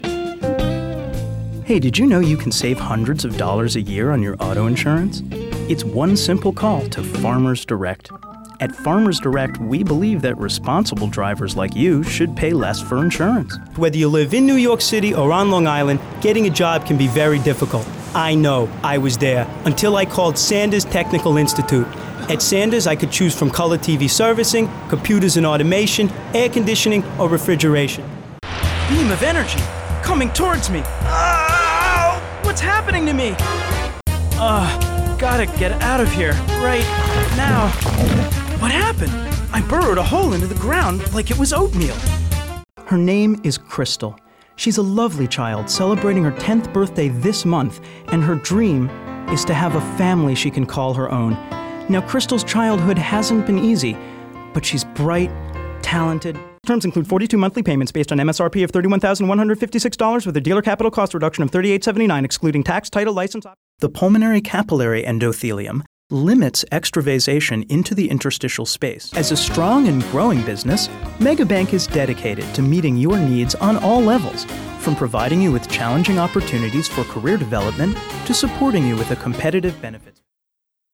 Engaging, Authentic, Articulate Storyteller
General (Standard) American, Brooklyn
Middle Aged